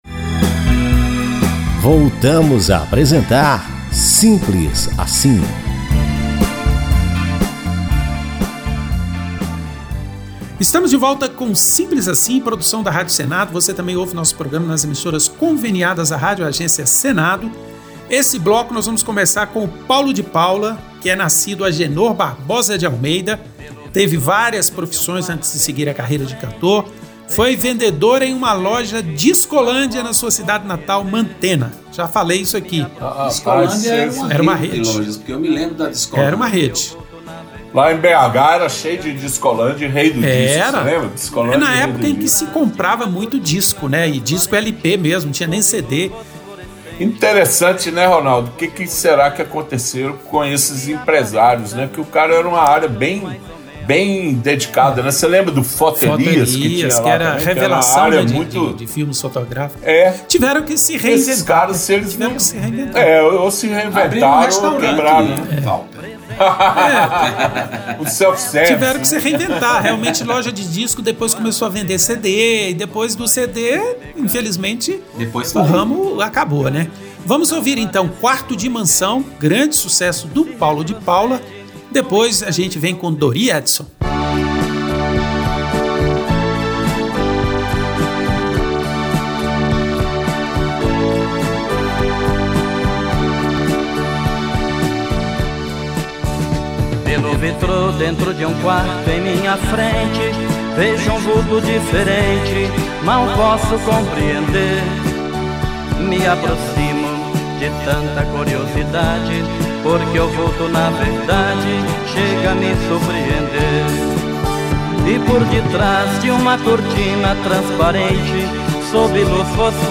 canções da Jovem Guarda